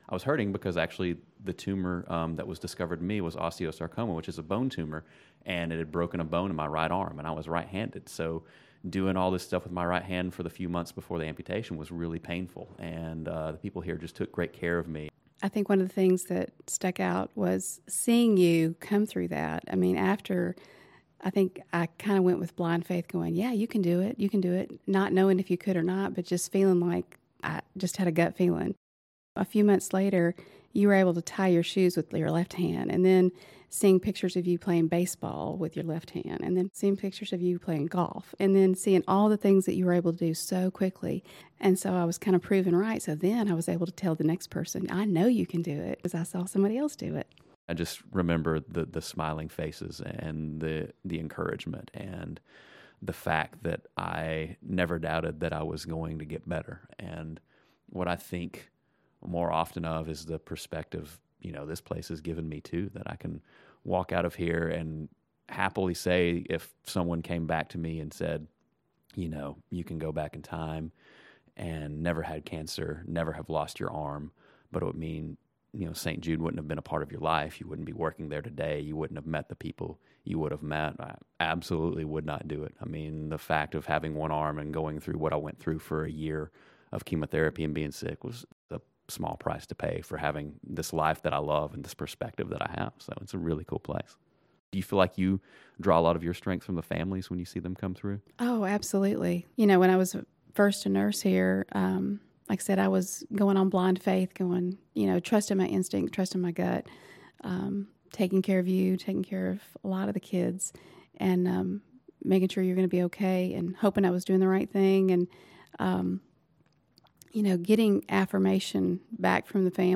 Partnering with the nonprofit oral history project StoryCorps, we asked families, faculty and staff to interview each other and record their shared moments in St. Jude history.